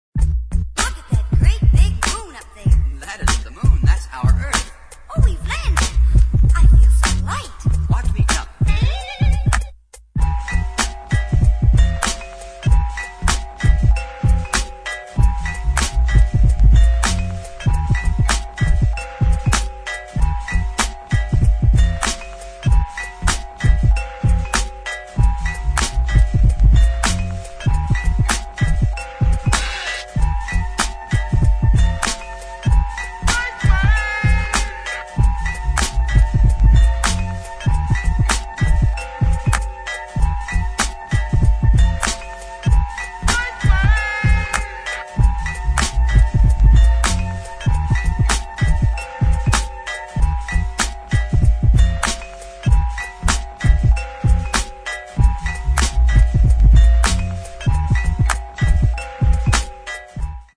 Nice fusion of dubstep, afro house and futuristic funk.
Electronix Hip Hop